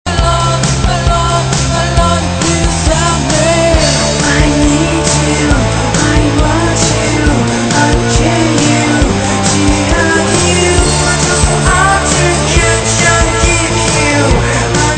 power-pop